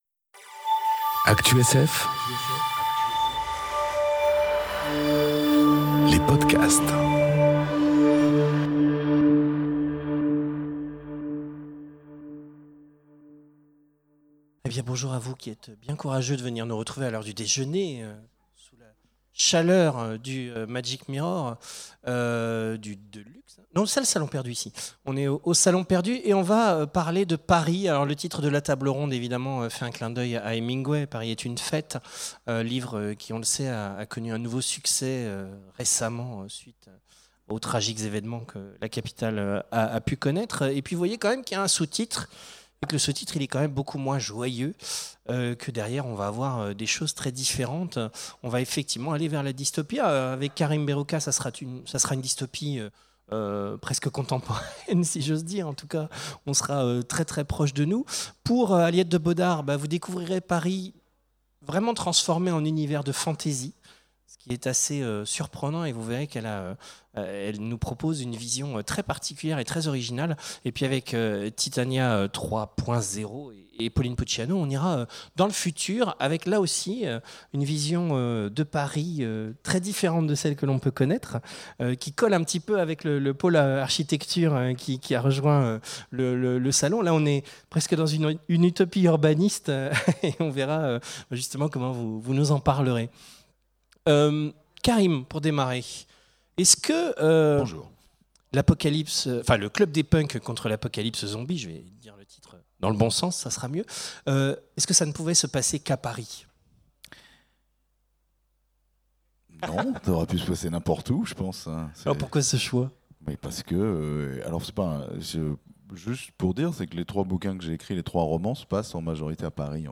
Conférence Paris est une fête ? Oui mais... Parfois salement dystopique ! enregistrée aux Imaginales 2018